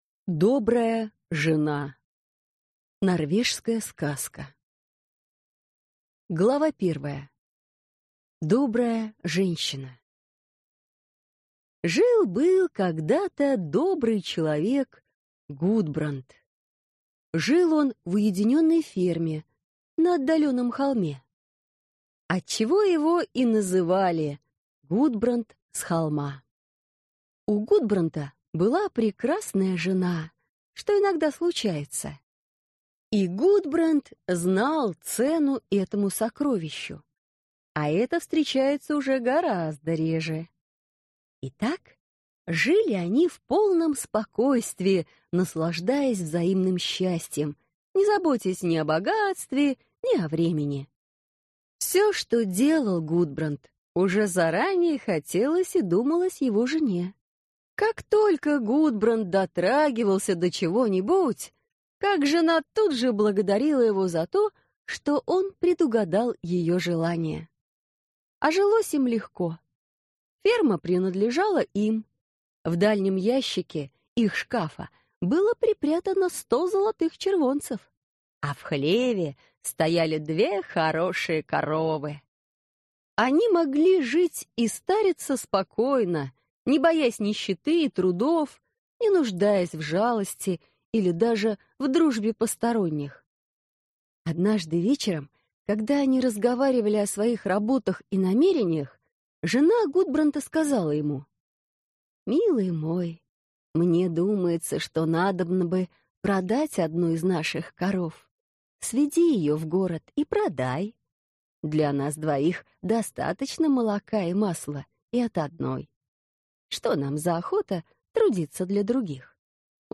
Аудиокнига Золотой Хлеб. Сказки | Библиотека аудиокниг